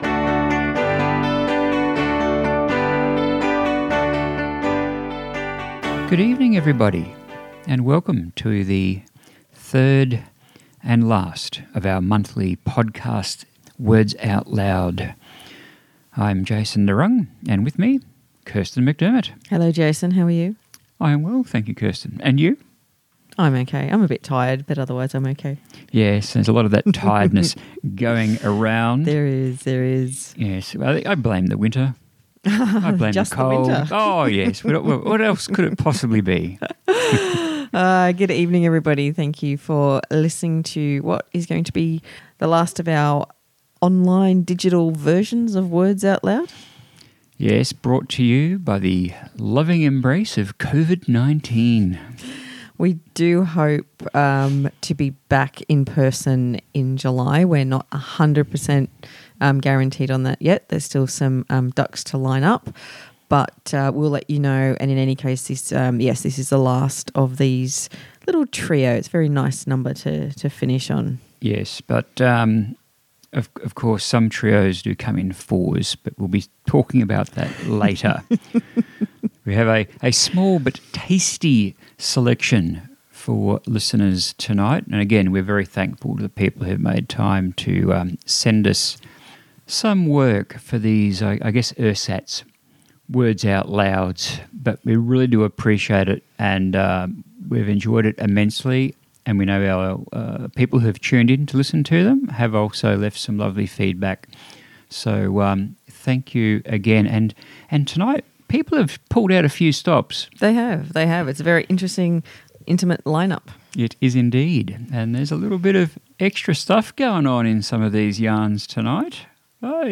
We greatly appreciate those who contributed using whatever recording device was at hand to get these to us.